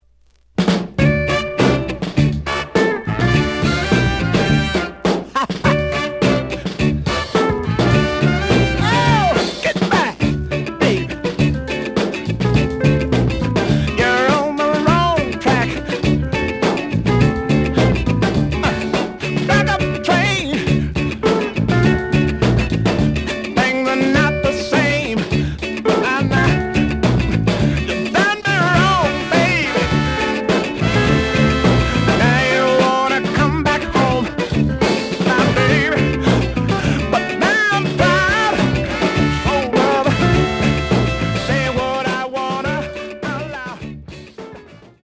The audio sample is recorded from the actual item.
●Genre: Soul, 70's Soul
B side plays good.)